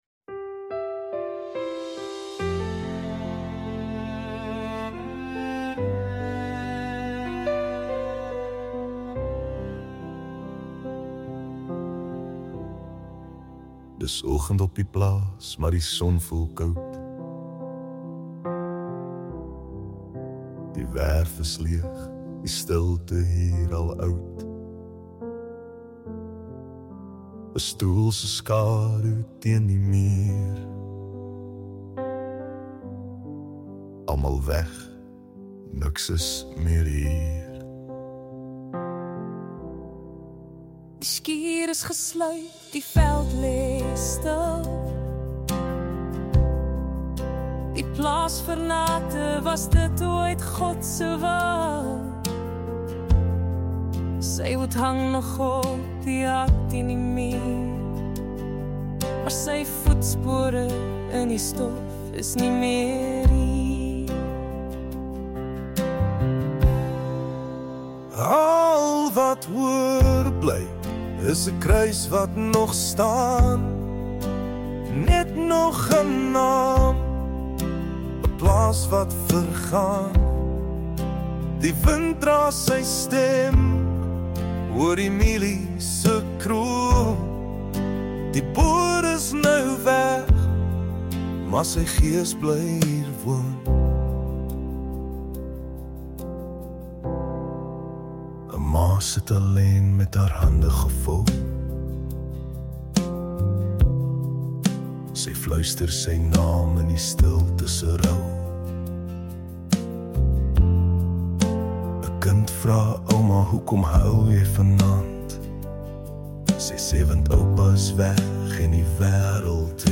Sad song about reality